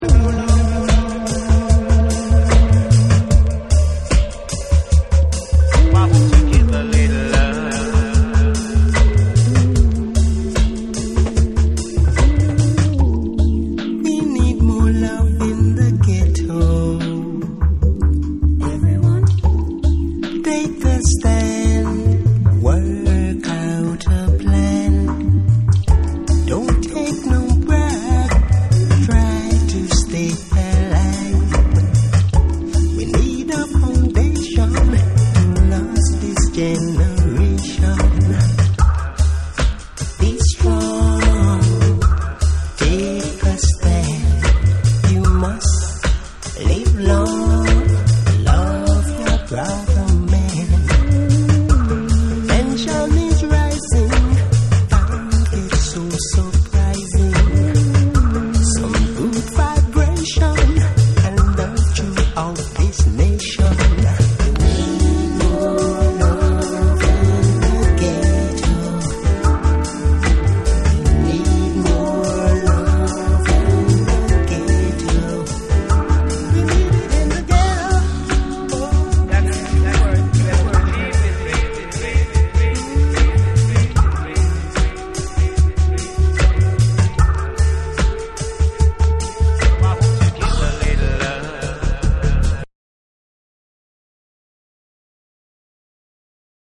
ダビーでスモーキーな12トラックを収録しています。
REGGAE & DUB / BREAKBEATS